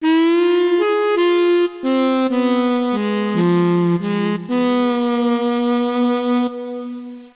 1 channel
horn3.mp3